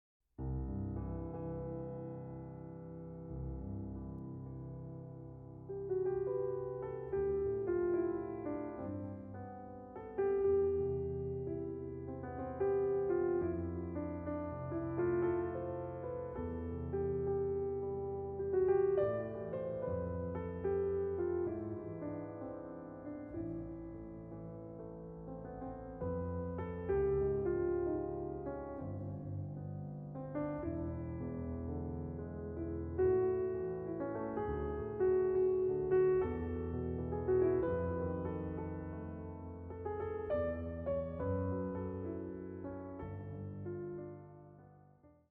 これらの「聴きやすい」曲にはクライマックスや終着点が無く、宙に浮くように美しい叙情性だけがいつまでも残っていきます。